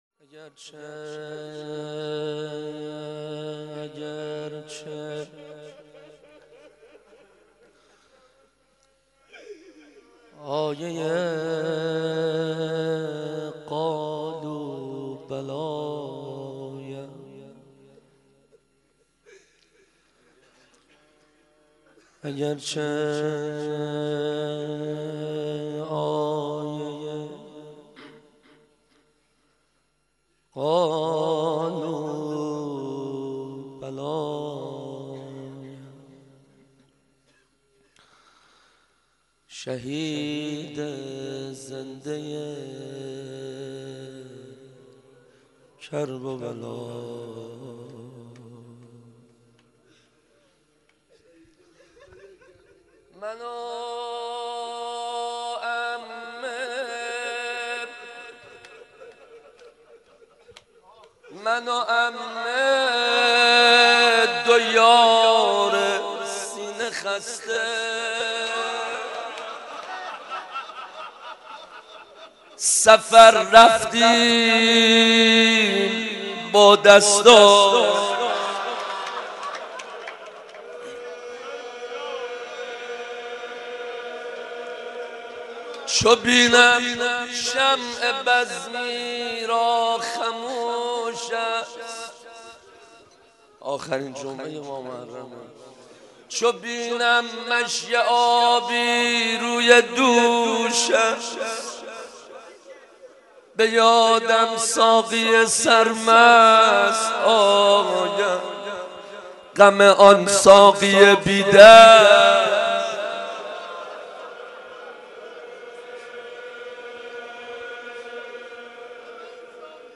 روضه شهادت امام سجاد ع